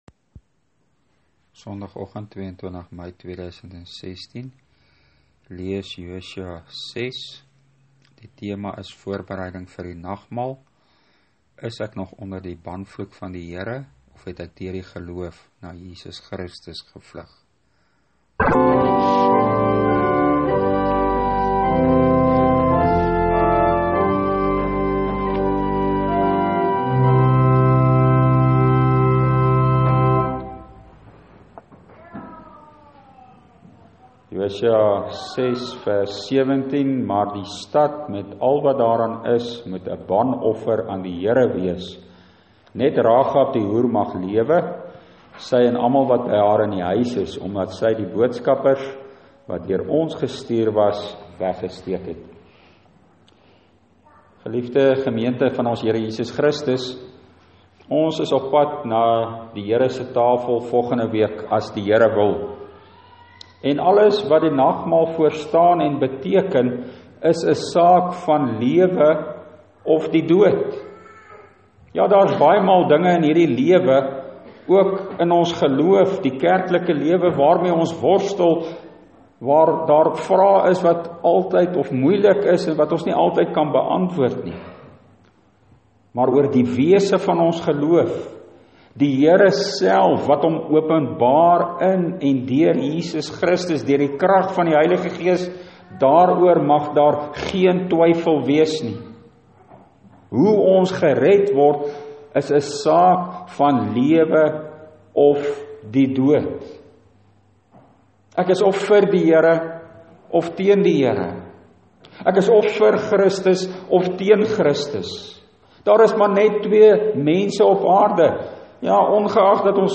Preekopname (GK Carletonville, 2016-05-22):